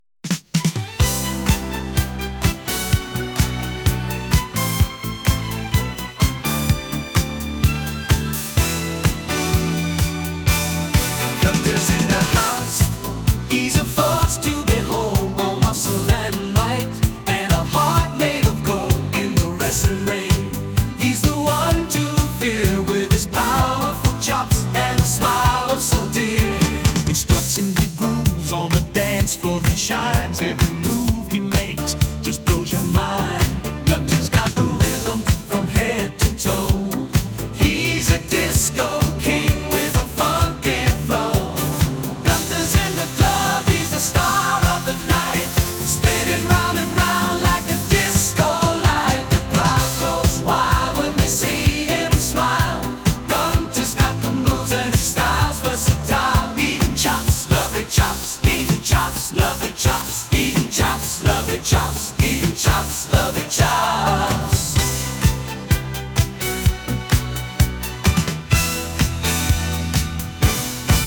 as performed by AI.